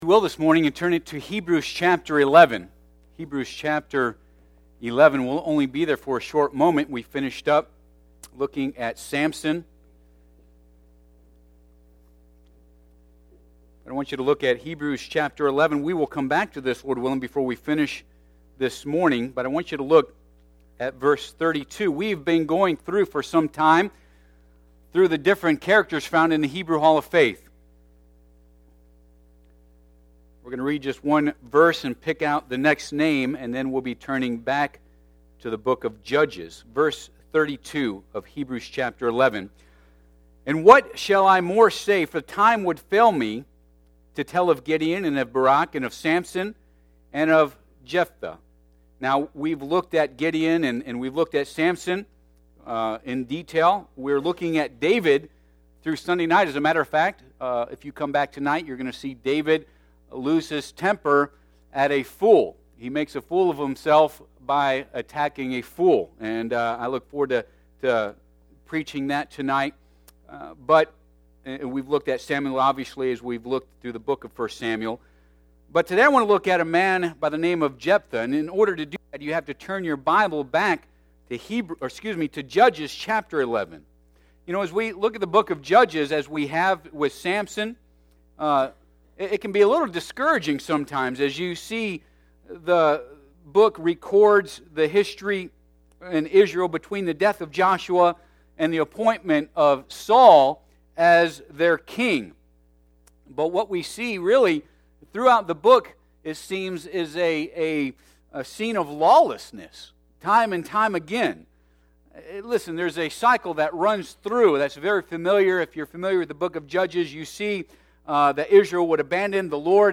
Passage: Judges 11 Service Type: Sunday AM Bible Text